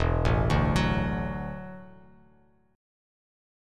E9sus4 chord